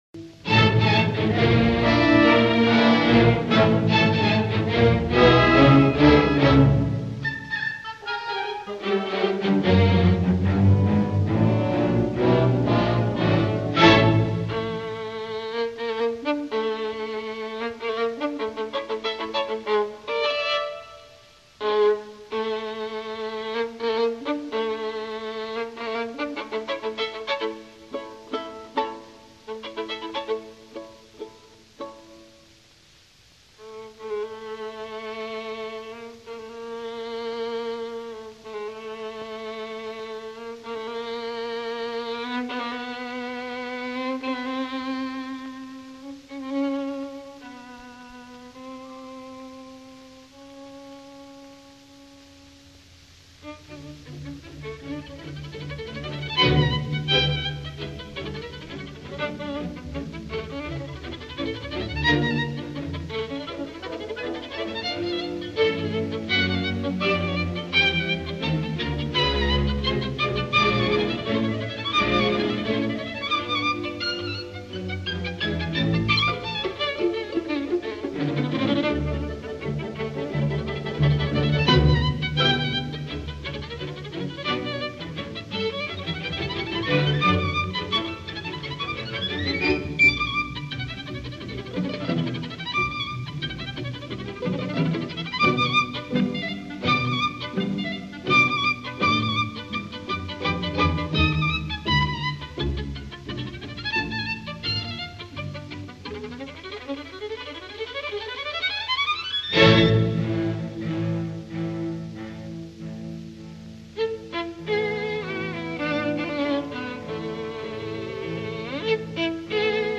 第三乐章,终曲,活泼的快板,D大调,2/4拍子,回旋奏鸣曲形式